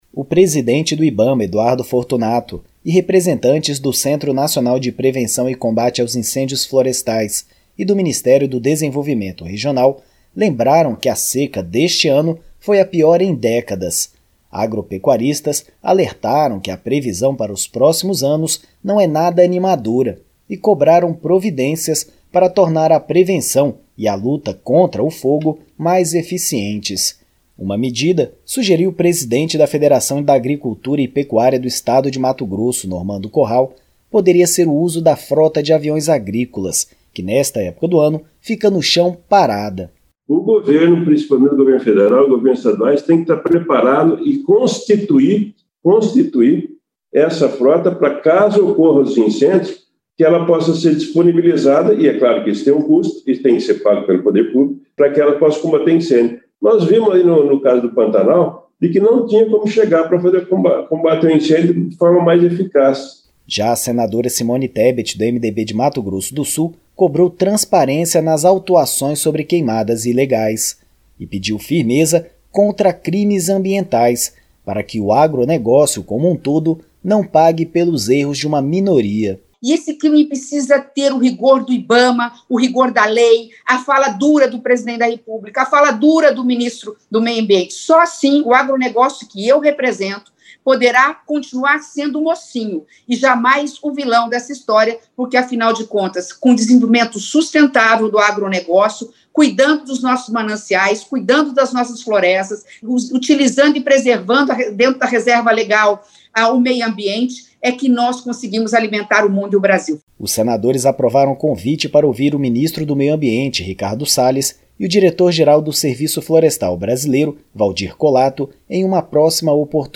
Já a senadora Simone Tebet (MDB-MS) cobrou firmeza contra crimes ambientais, para que o agronegócio não pague pelos erros de uma minoria. A reportagem